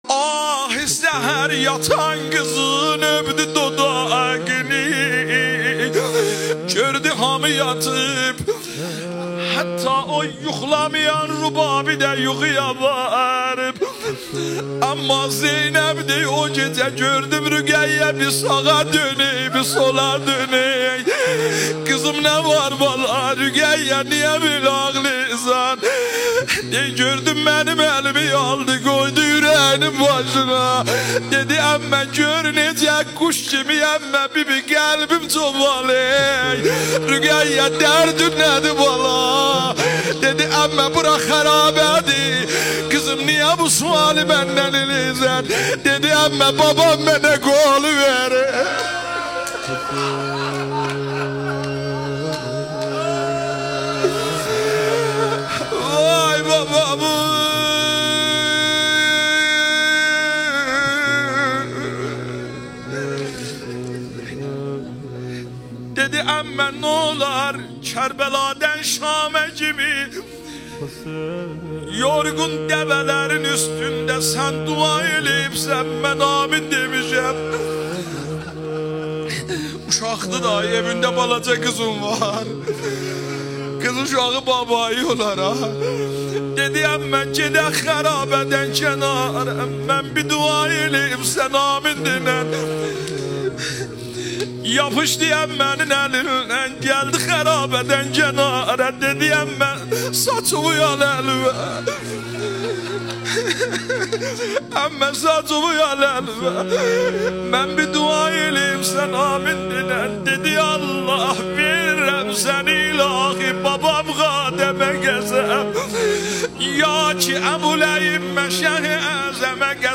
روضه حضرت رقیه سلام الله علیها